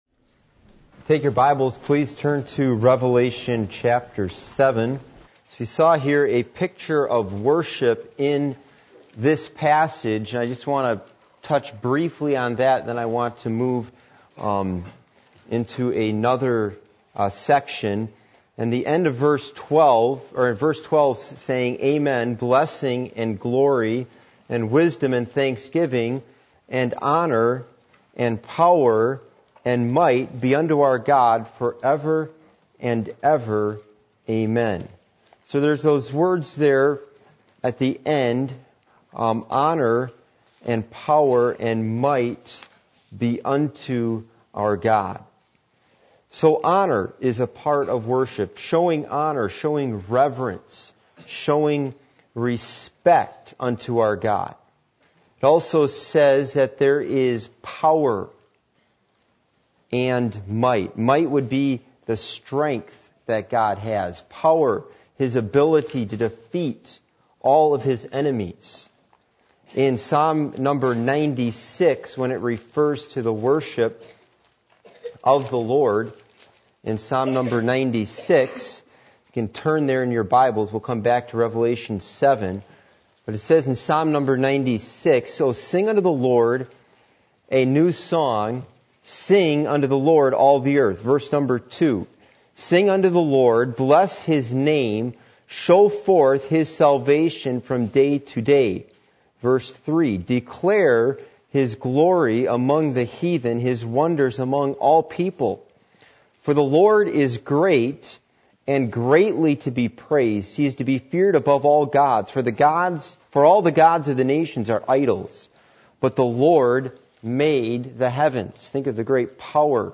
Passage: Revelation 7:12 Service Type: Midweek Meeting %todo_render% « Stepping Out By Faith In The Calling Of God Why Is Jesus Called “The Word” ?